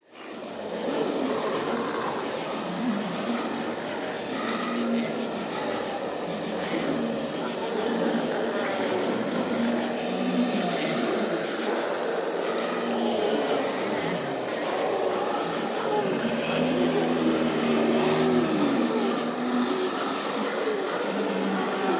Шепот мертвецов на кладбище ночью